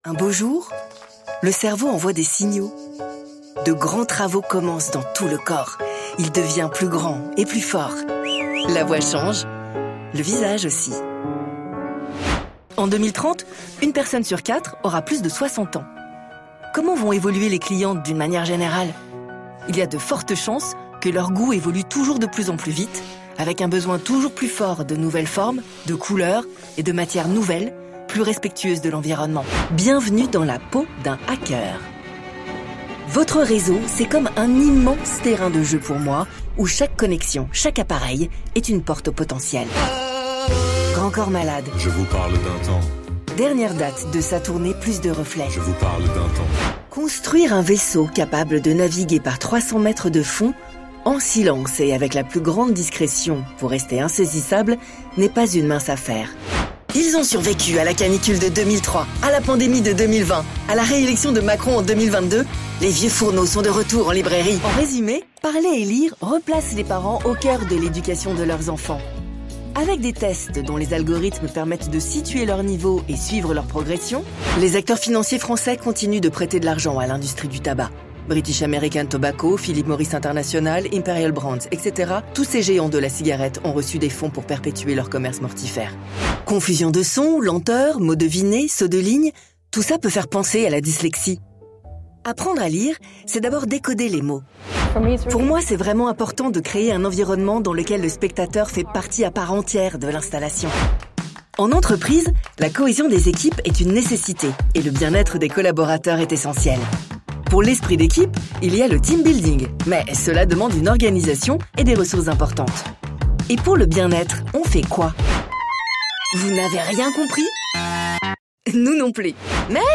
Voix-off femme pour vidéo de vulgarisation, modules de formation elearning, podcasts, livres audio, serious games... enregistrement à distance ou en studio